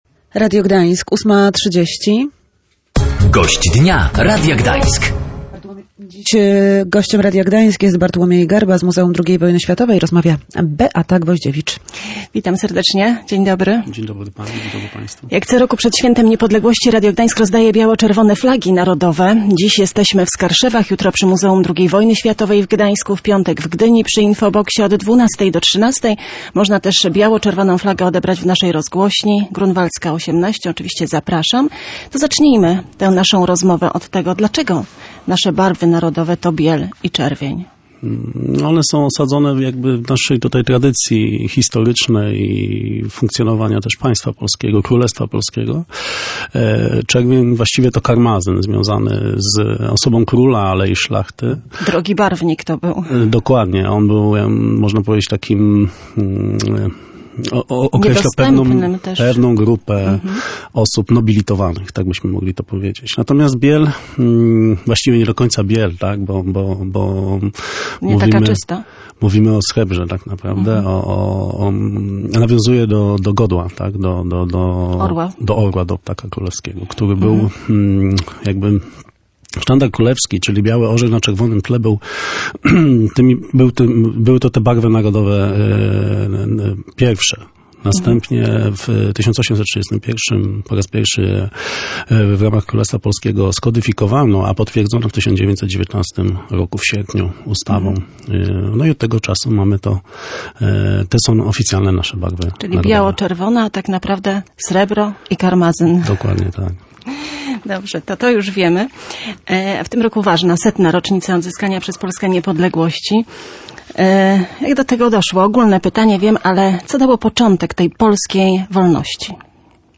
Ta data ukształtowała się w roku 1926, po przewrocie majowym, gdy Piłsudski miał pełnię władzy – mówił historyk w Radiu Gdańsk.